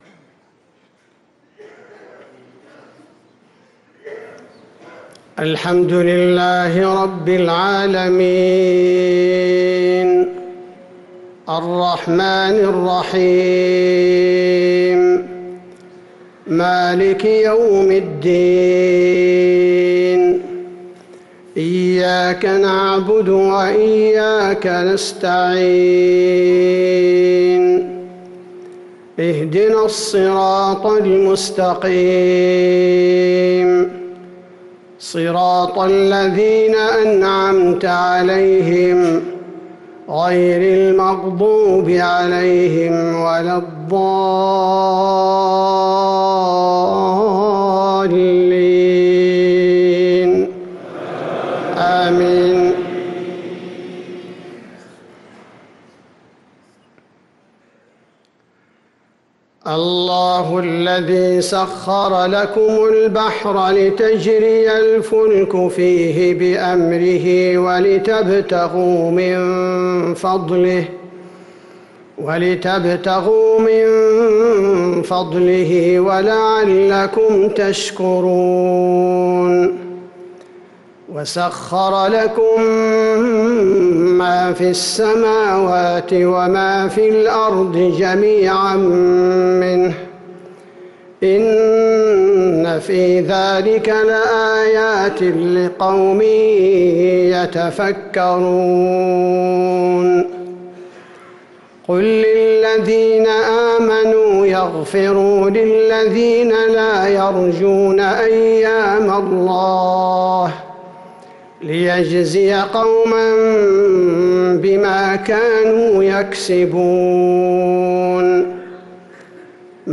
صلاة العشاء للقارئ عبدالباري الثبيتي 27 جمادي الأول 1445 هـ
تِلَاوَات الْحَرَمَيْن .